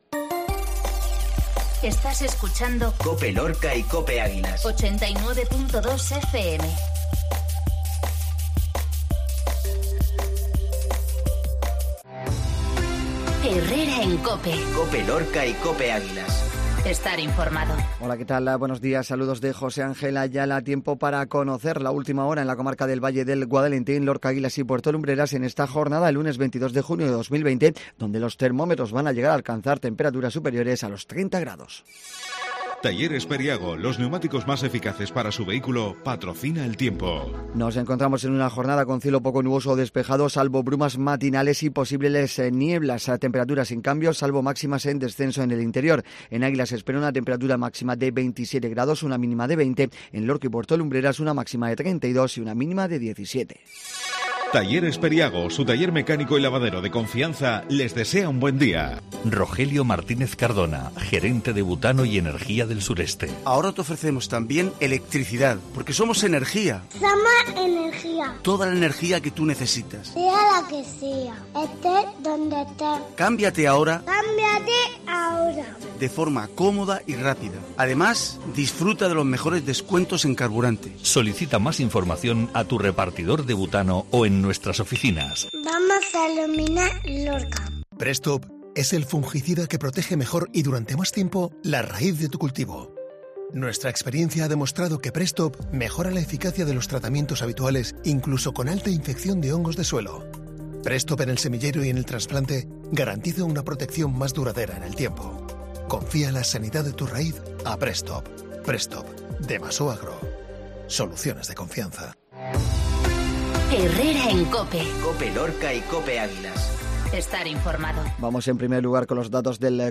INFORMATIVO MATINAL LUNES 2206